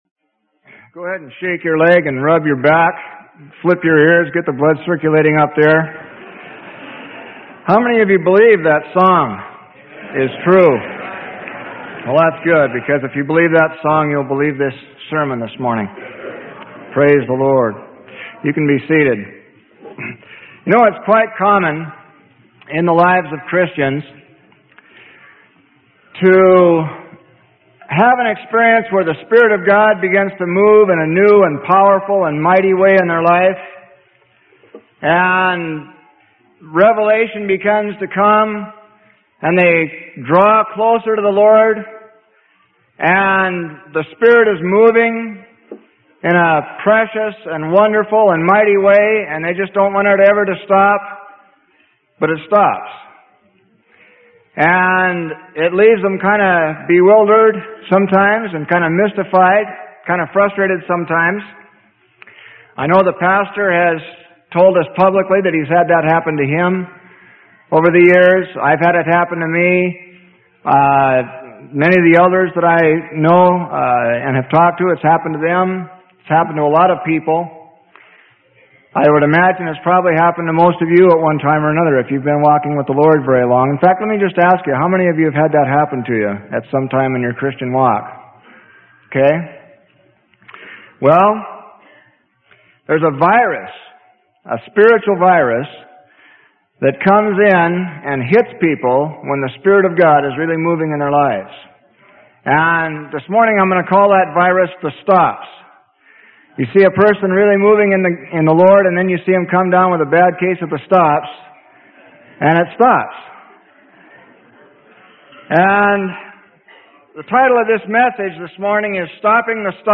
Sermon: Stopping the Stops - Freely Given Online Library